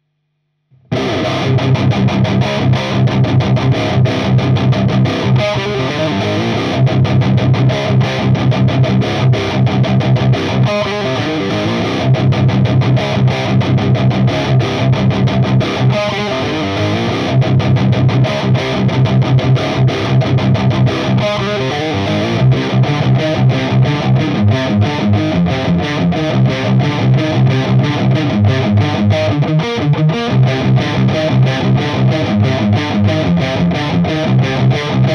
Voici 4 sample de chaque canal en Vintage/Modern.
Gain 15h